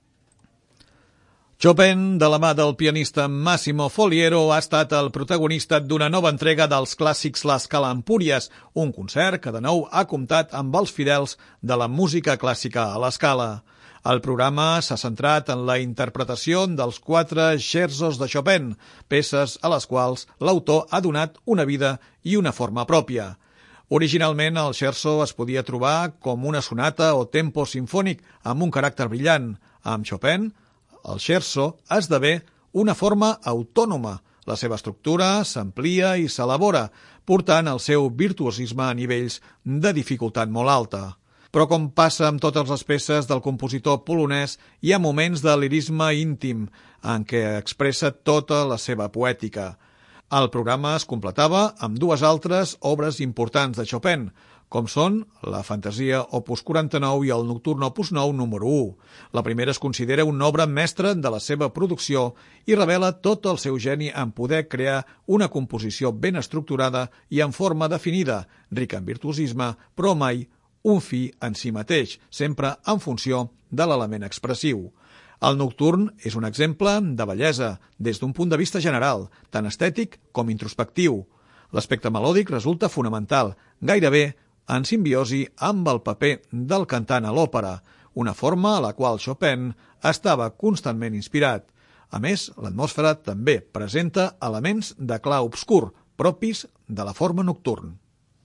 La música de Chopin omple l'església de Sant Martí d'Empúries en un nou concert dels Clàssics l'Escala-Empúries.